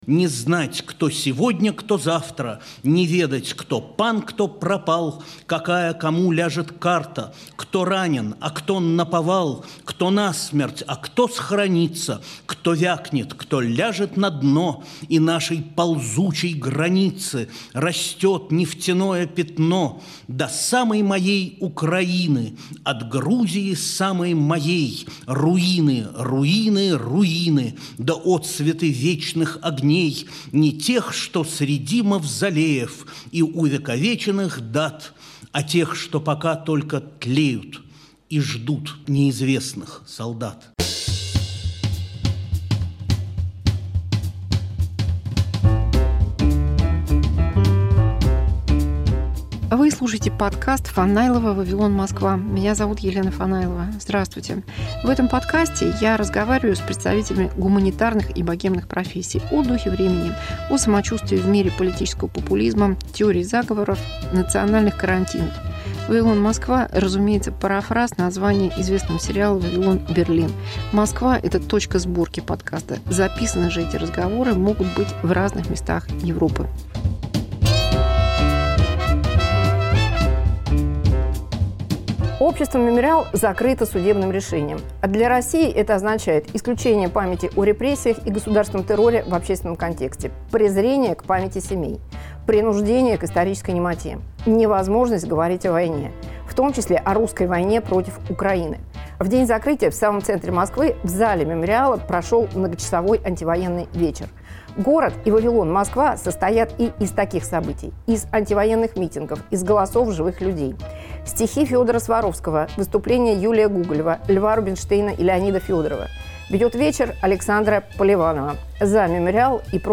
Поэты, актеры и музыканты на антивоенном вечере в "Мемориале". Часть 1